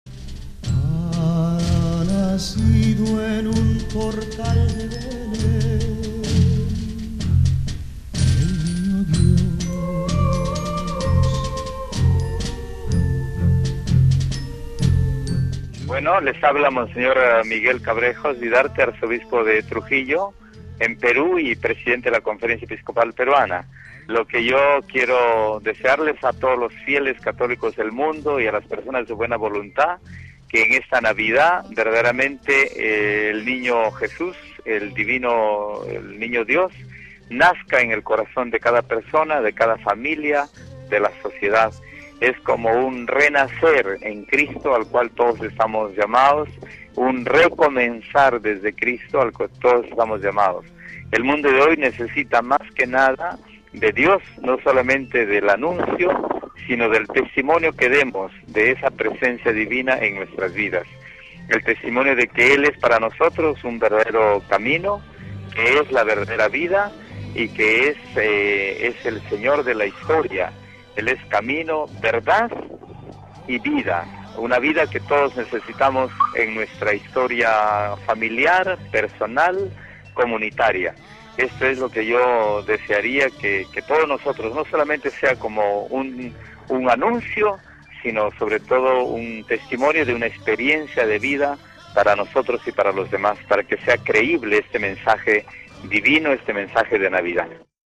Cuando estamos celebrando este período navideño los obispos, los pastores de América Latina desde sus países se dirigen a todos nuestros oyentes con un mensaje de Navidad.